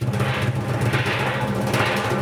BON ROLL1A.wav